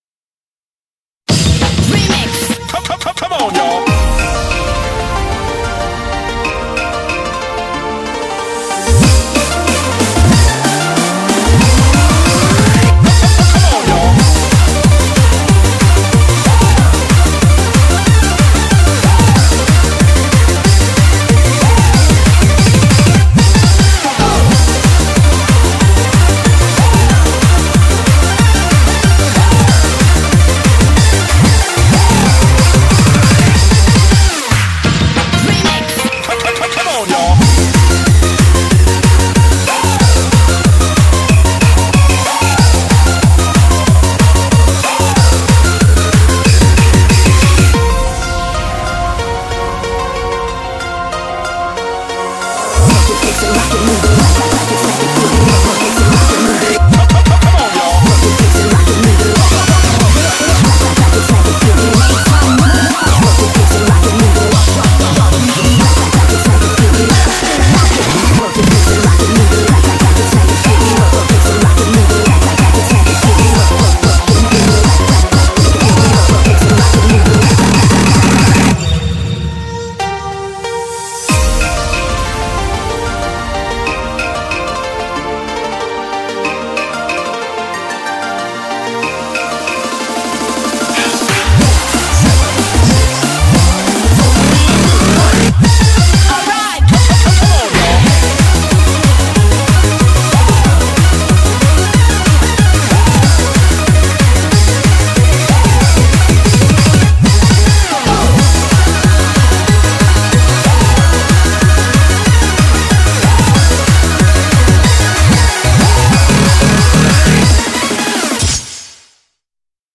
BPM93-186